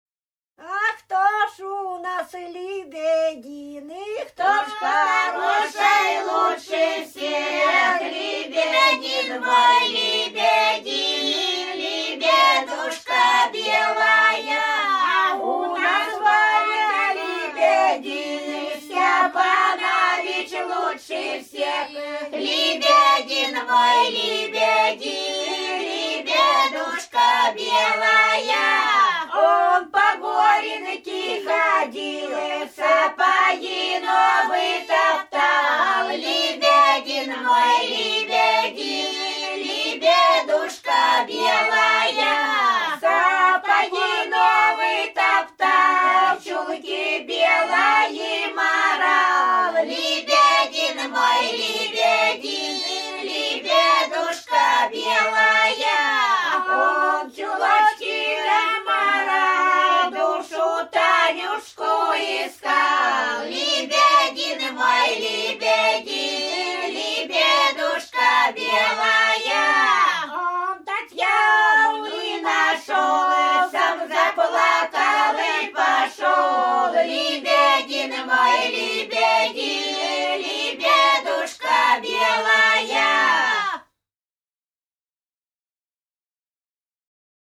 Рязань Кутуково «А кто ж у нас либедин», свадебная плясовая.